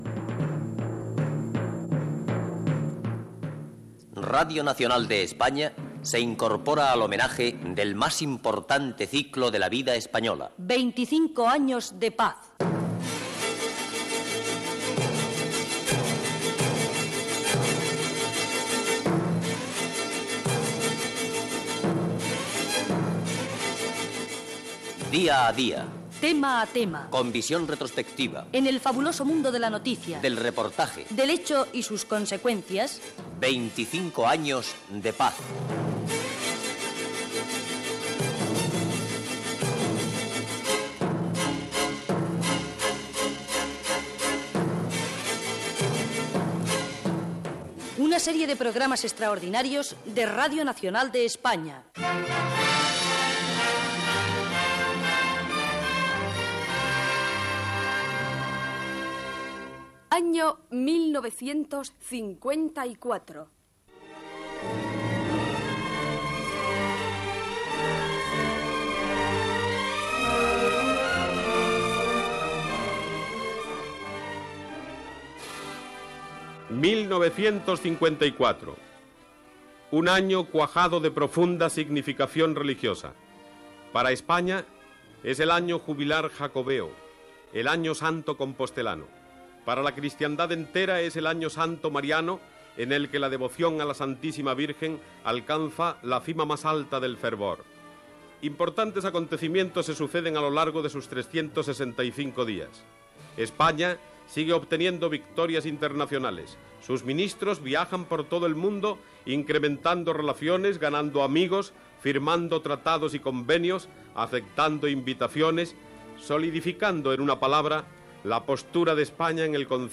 Careta del programa, l'any 1954, la projecció internacional d'Espanya, rebuda dels repatriats a Rússia, etc.
Divulgació